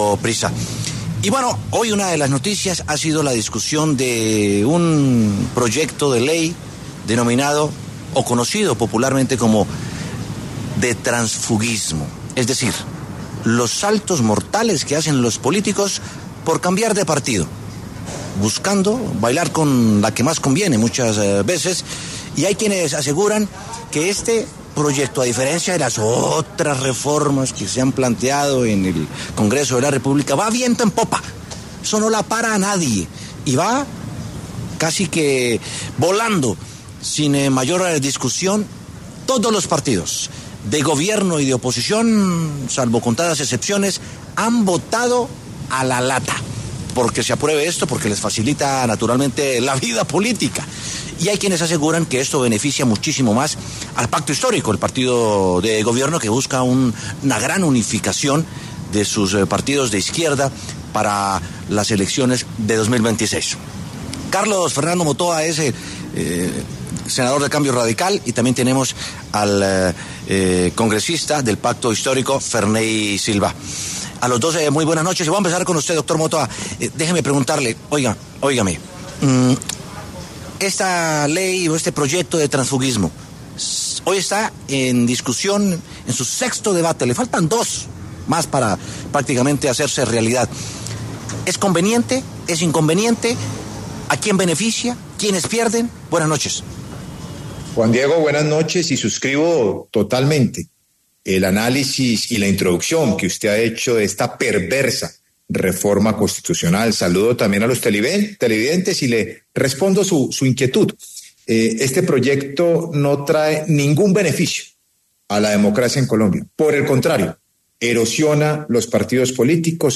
Debate: ¿El transfuguismo trae beneficios para la democracia colombiana?
Carlos Motoa del Cambio Radical y Ferney Silva del Pacto Histórico, pasaron por los micrófonos de W Sin Carreta y hablaron al respecto.